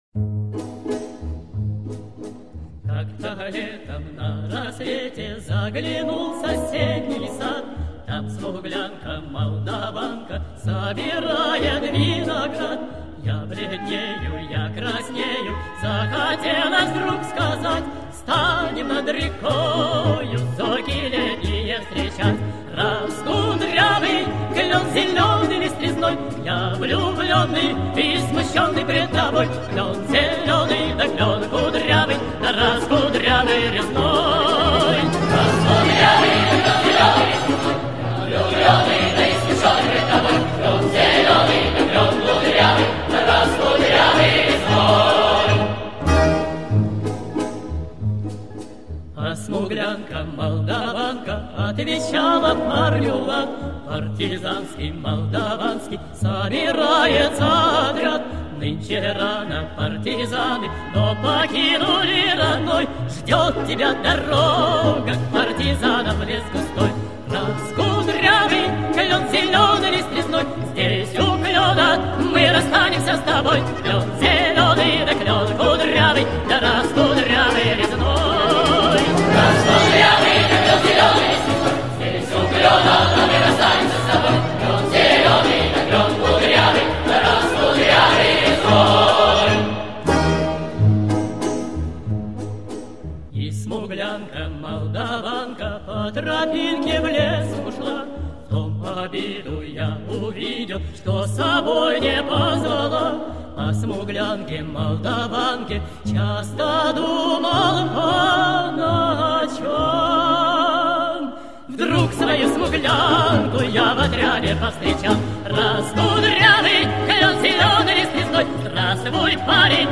Категория: speed up